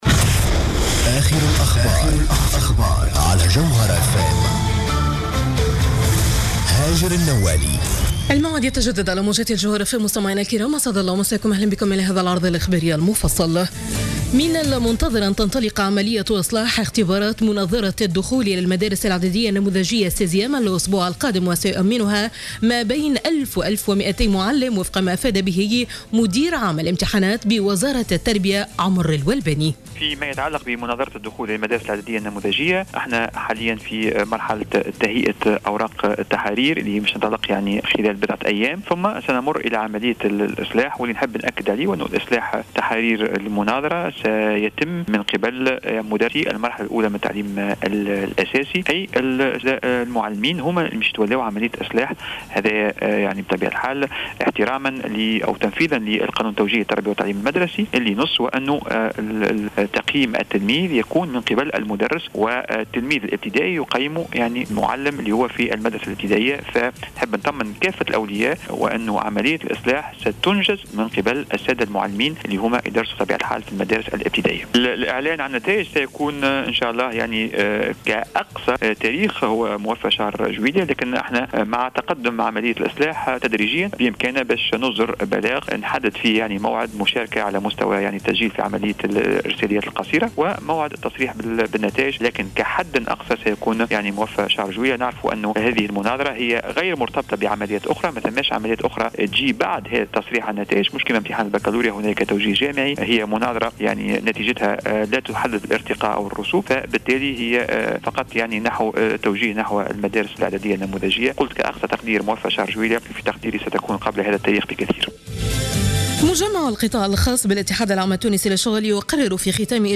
نشرة أخبار منتصف الليل ليوم الاربعاء 24 جوان 2015